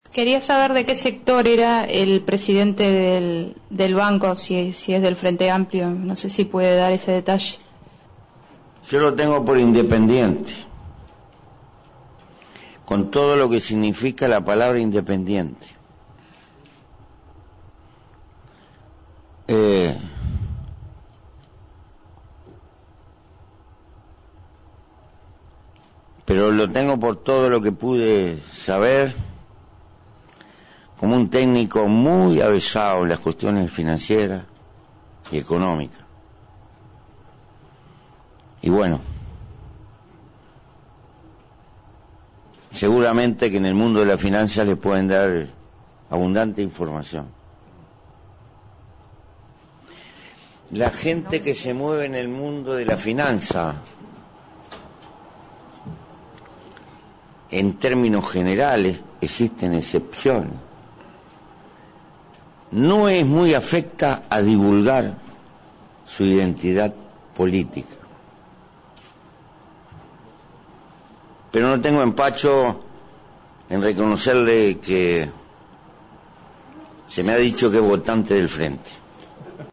Así lo informó el presidente José Mujica en conferencia de prensa.